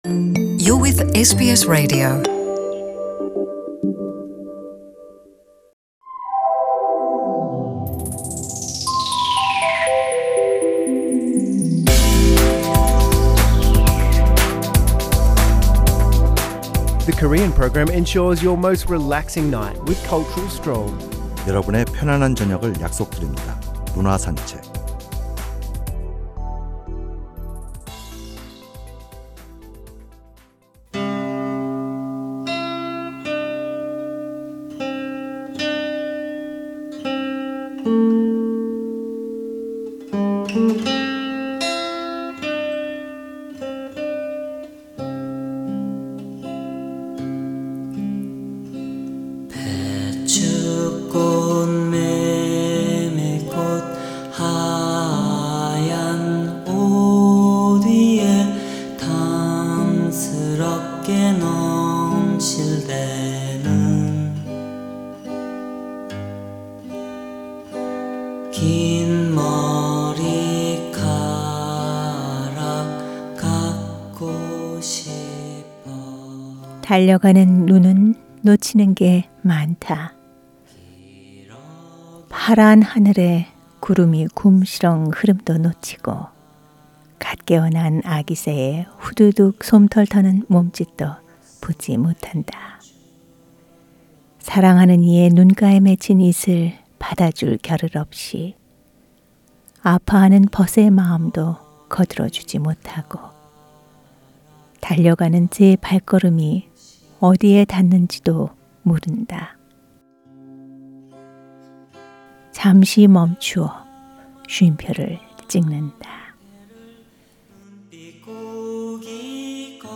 The Weekly Culture Odyssey looks into arts and artists' life with background music, and presents a variety of information on culture, which will refresh and infuse with intellectual richness.